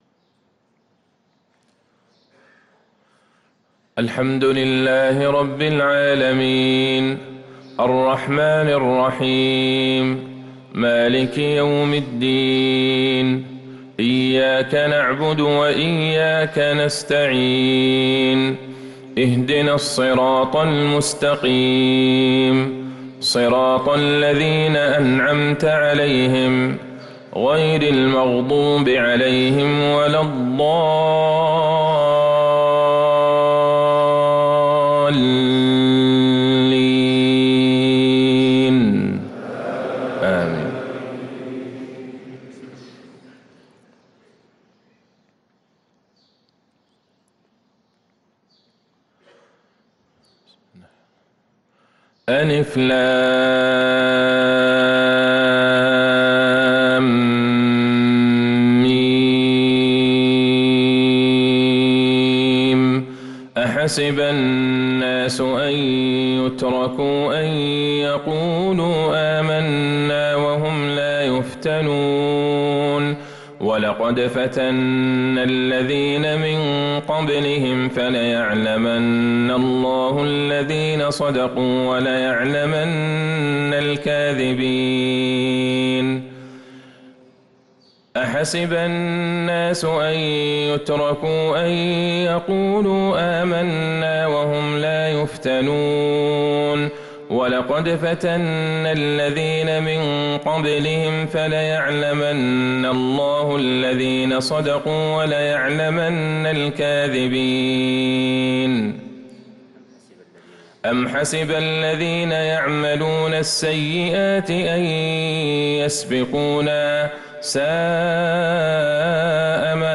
صلاة الفجر للقارئ عبدالله البعيجان 18 جمادي الآخر 1445 هـ
تِلَاوَات الْحَرَمَيْن .